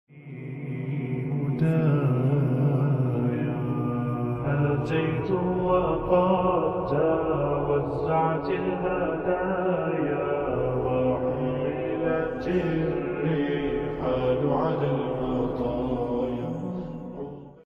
slowed version